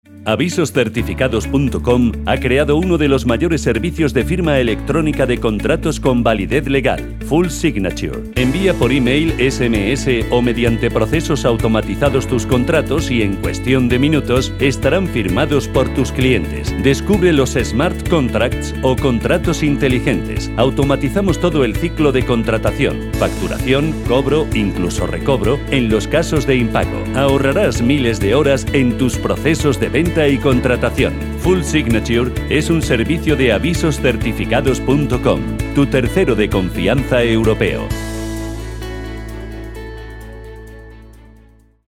Presentación de Servicio de Firma de Contratos Eléctricos AUDIO SPOT
3-spot-audio-firma-contratos-avisos-certificados.mp3